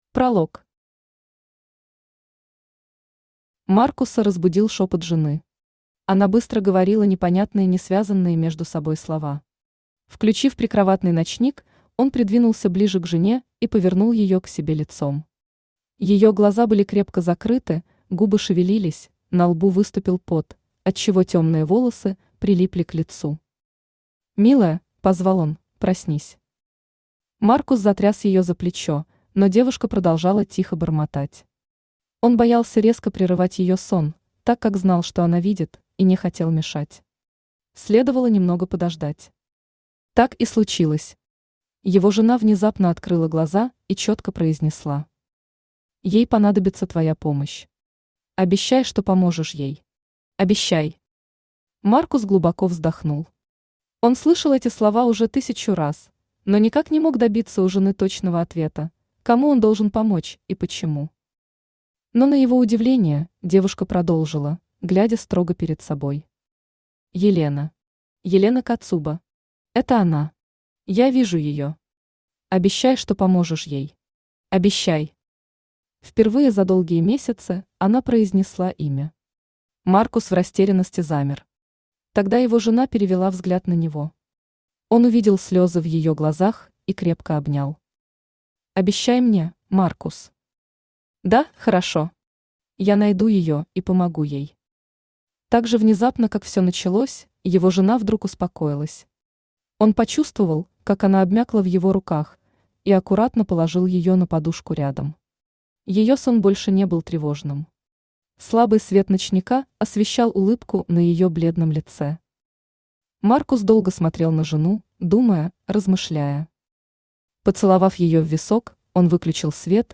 Аудиокнига Миллион лет молчания | Библиотека аудиокниг
Aудиокнига Миллион лет молчания Автор Алина Миларут Читает аудиокнигу Авточтец ЛитРес.